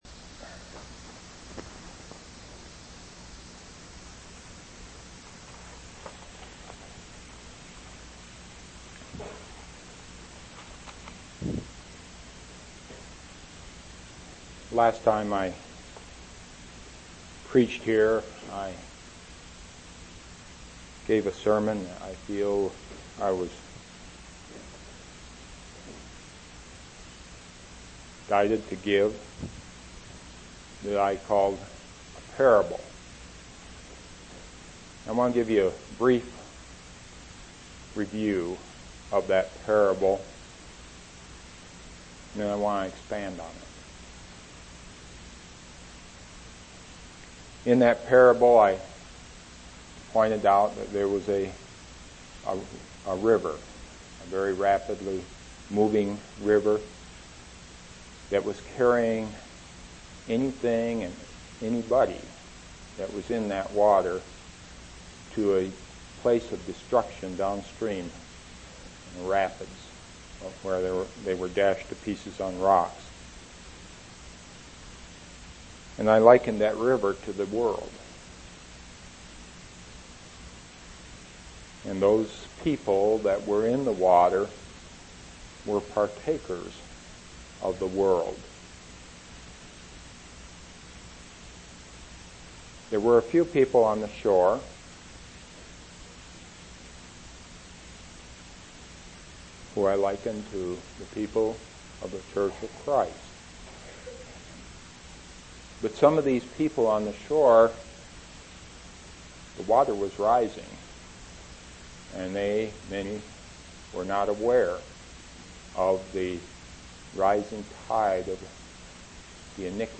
10/14/1990 Location: East Independence Local Event